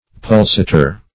Search Result for " pulsator" : The Collaborative International Dictionary of English v.0.48: Pulsator \Pul*sa"tor\, n. [L.] 1. A beater; a striker.